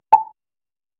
ドリップ